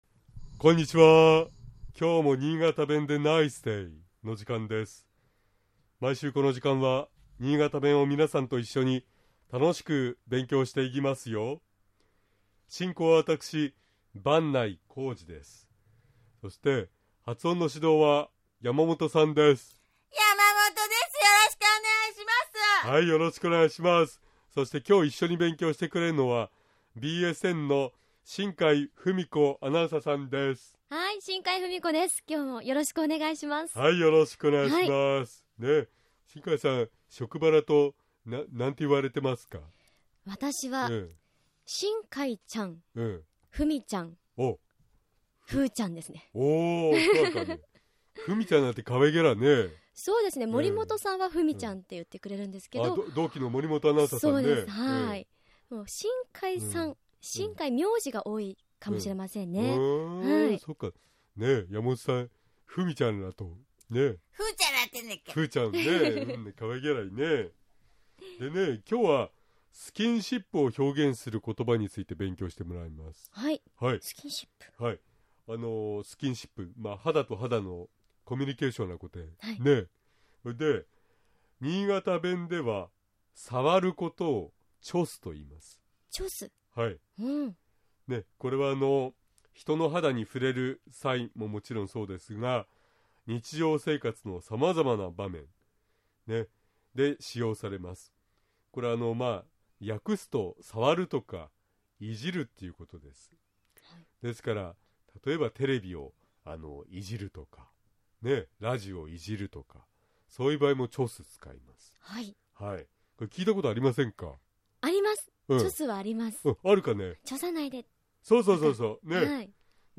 今回は、スキンシップを表現する言葉について勉強しましょう。 まず、新潟弁では触る事を「ちょす」と言います。